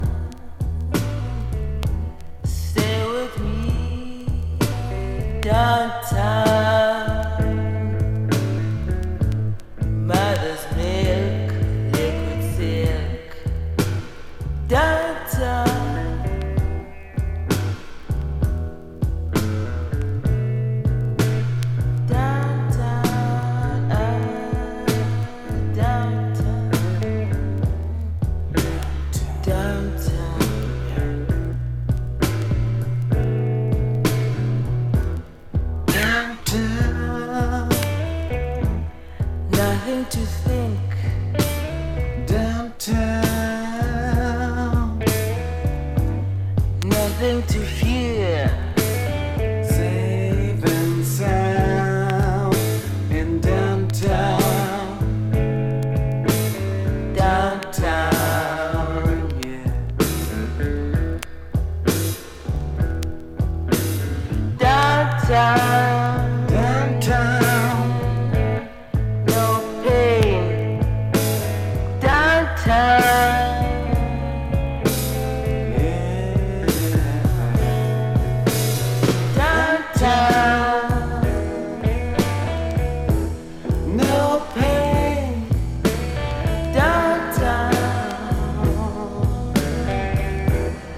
前半がスタジオ・ライヴ、後半はライヴ録音。